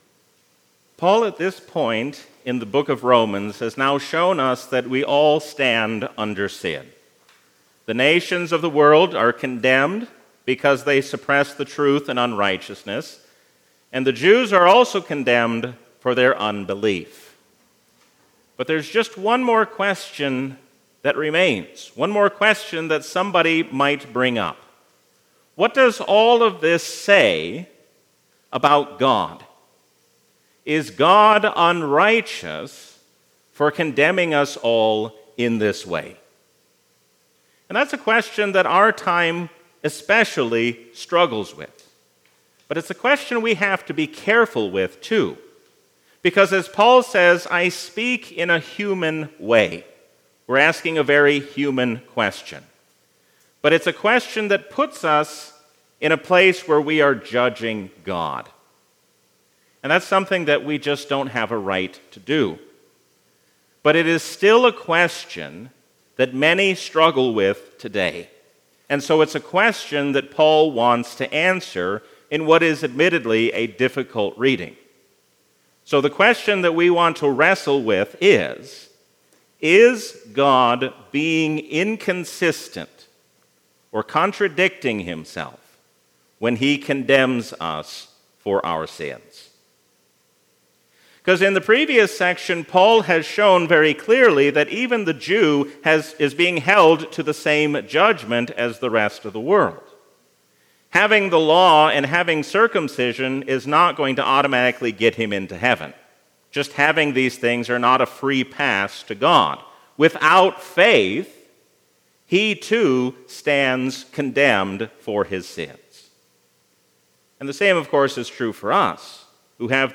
A sermon from the season "Trinity 2024." There is no reason to worry about Tuesday or any day to come when we remember that the Lord reigns as King forever.